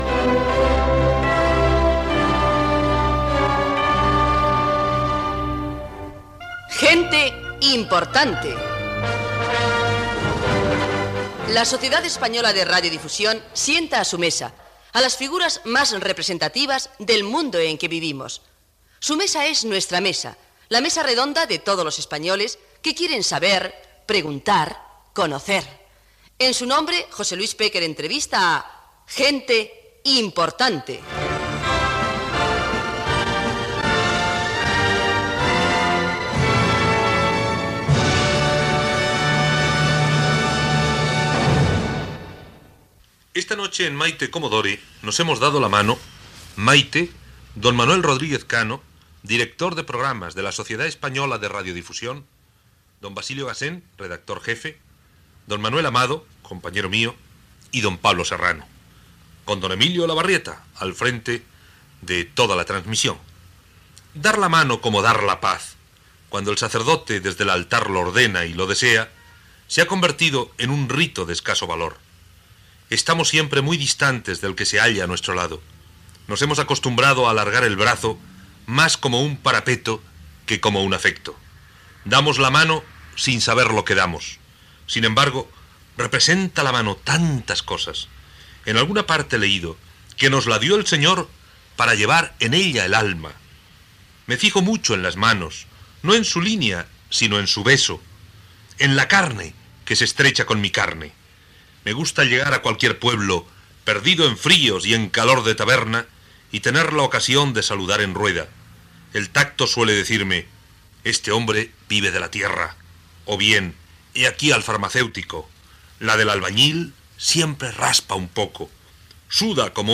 Careta del programa, equip, les mans dels diferents professionals, impressions de Mayte Commodore sobre l'invitat al programa, entrevista a l'escultor Pablo Serrano
Entreteniment
Pécker, José Luis